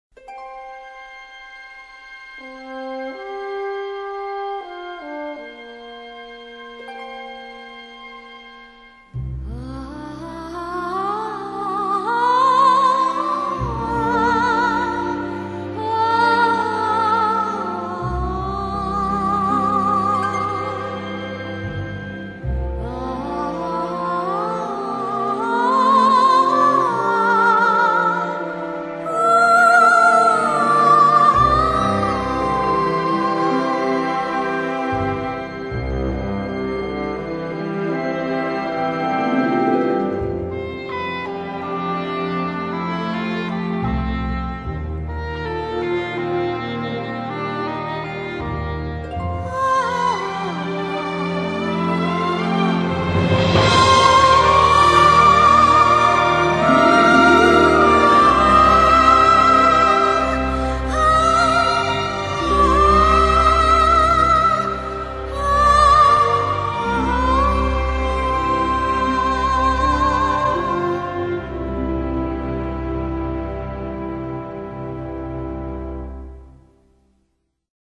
부드러운 느낌의 곡.
왜 시작할 때 여자 보컬 '아아아 아아~' 하는 곳 있죠? 그겁니다.